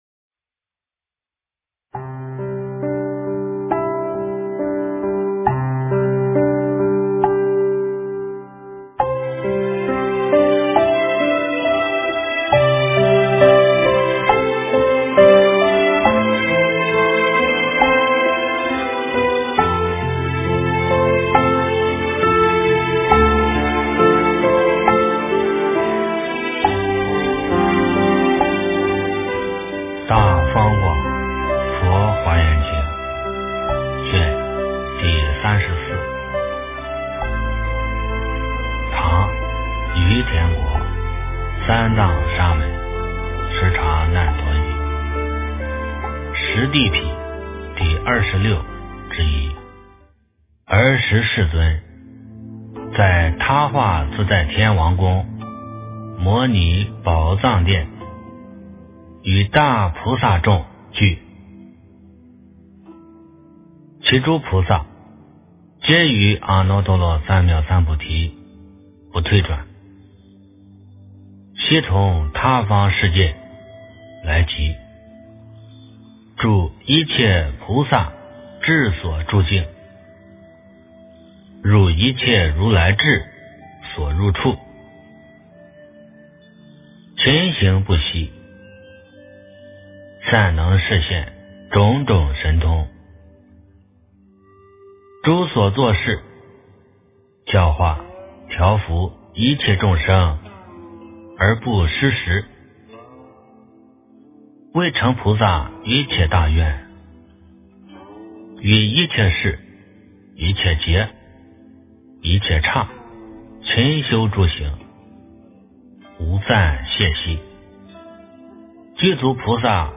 诵经
佛音 诵经 佛教音乐 返回列表 上一篇： 《华严经》33卷 下一篇： 《华严经》38卷 相关文章 月满弦(纯音乐